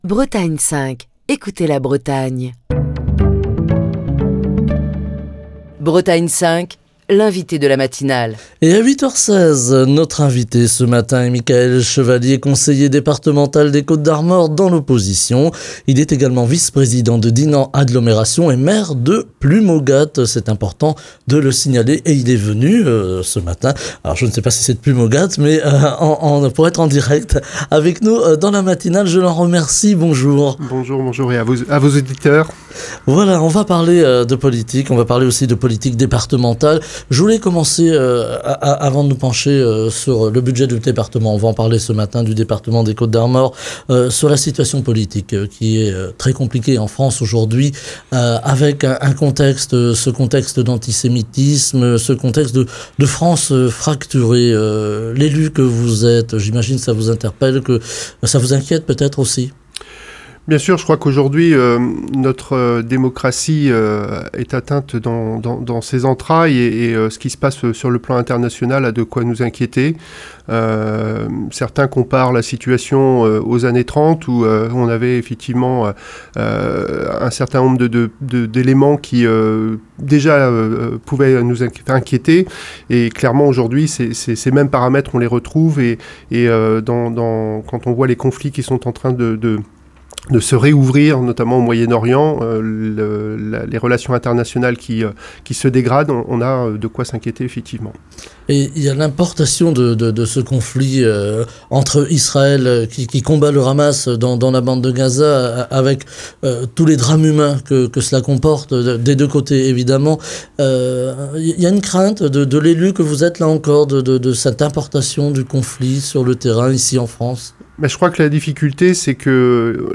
Ce mercredi, nous revenons sur le budget du département des Côtes d'Armor qui inquiète l'opposition départementale, dans un contexte de contraintes économiques et de baisse des recettes avec Mickaël Chevalier, conseiller départemental des Côtes d'Armor dans l'opposition (Union du centre et de la droite), vice-président de Dinan Agglomération, maire de Plumaugat, est l'invité politique de Bretagne 5 Matin.
Écouter Télécharger Partager le podcast Facebook Twitter Linkedin Mail L'invité de Bretagne 5 Matin